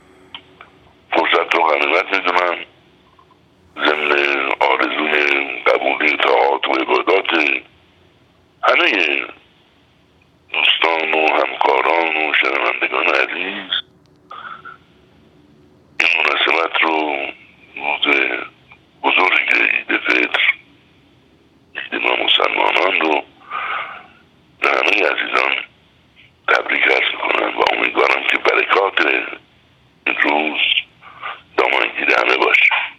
بهروز رضوی در گفت‌وگو با ایکنا: